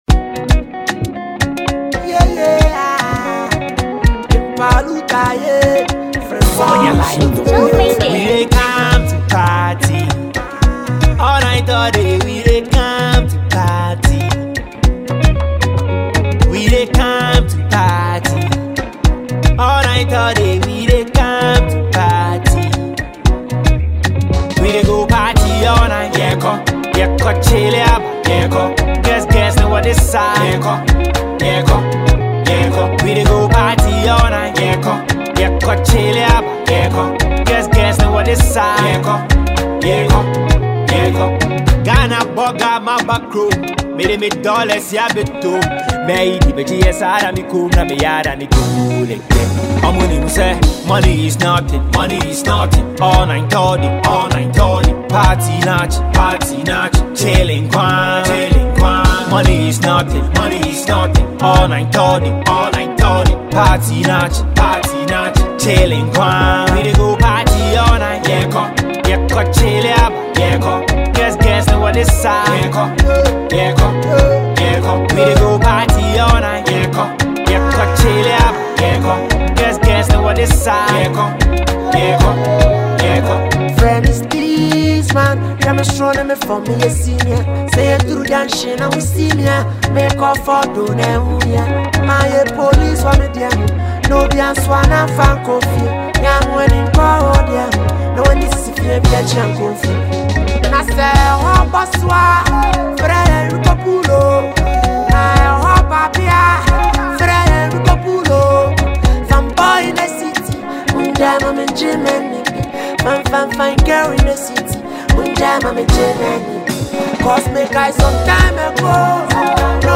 smooth vocals
Afrobeat and hip-hop music